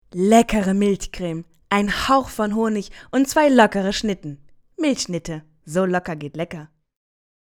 rheinisch
Sprechprobe: Werbung (Muttersprache):